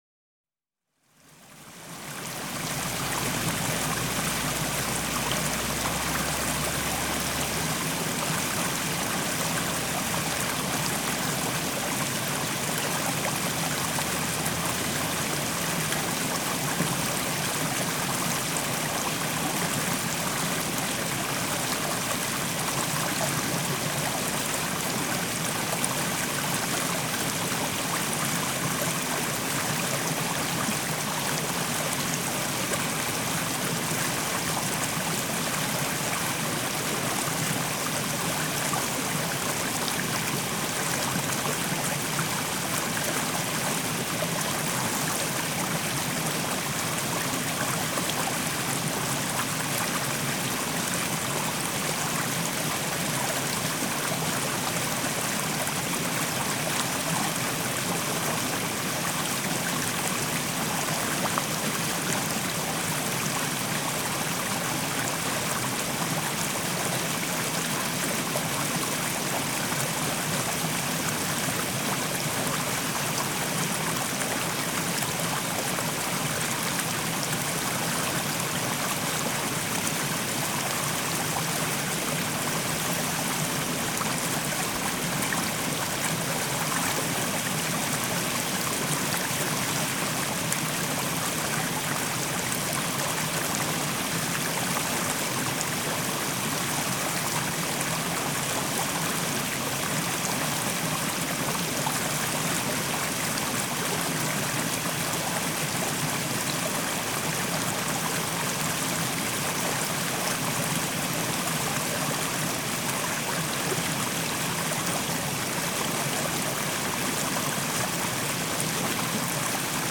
Водоемы звуки скачать, слушать онлайн ✔в хорошем качестве